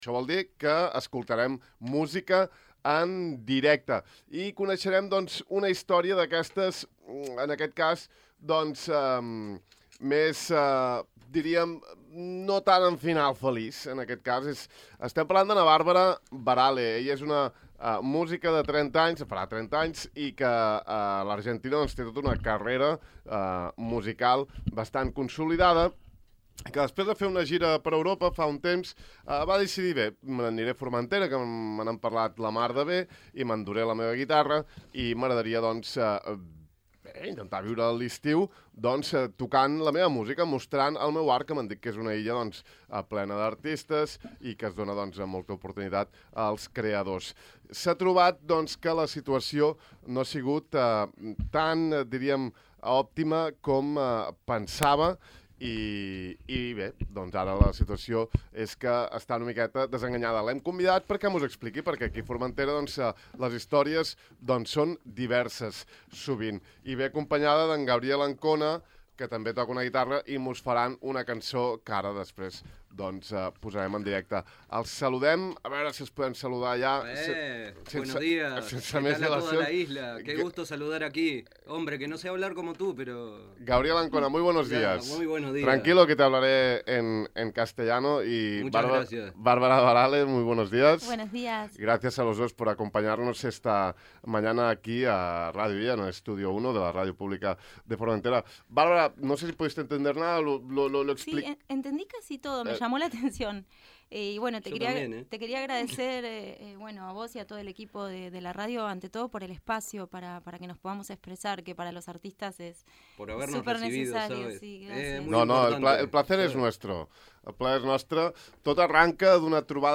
Avui l’hem tingut al De far a far explicant-nos tot això i cantant-nos sobre Formentera.